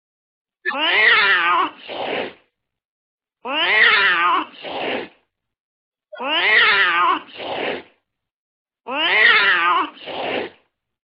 Arg Katt (Ljudeffekter)